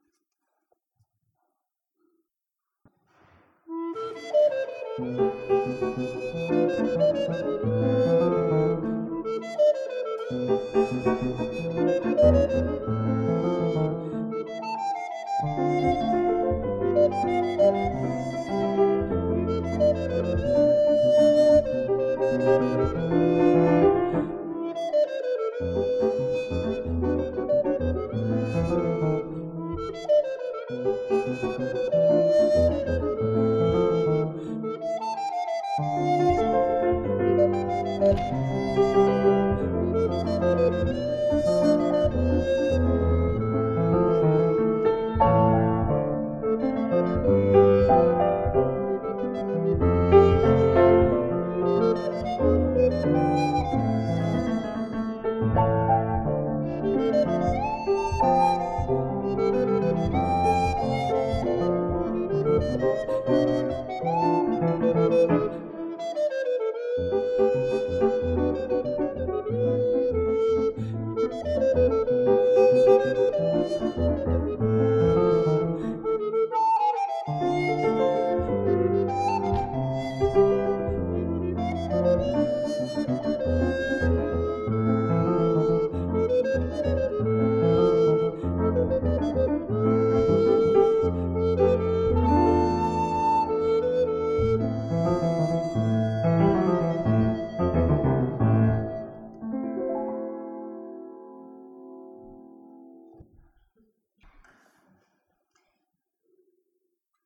Flötenmusik